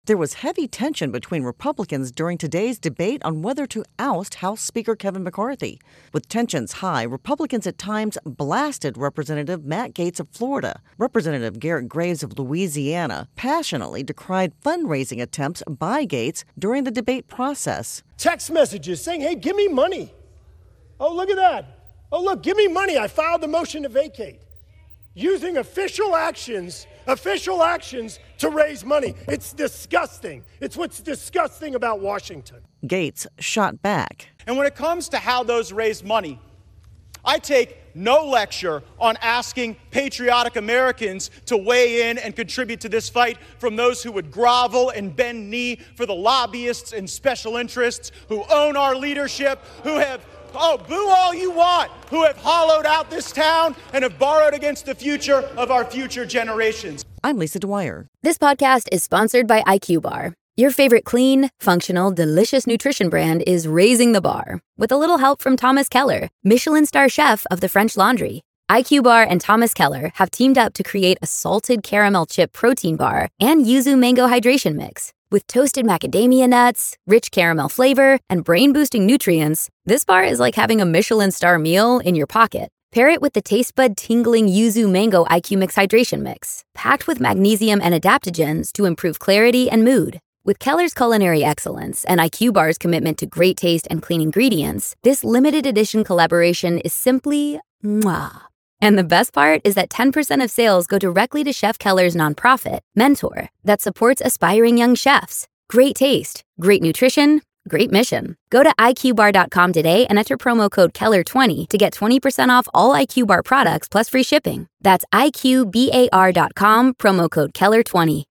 (Graves/Gaetz interchange))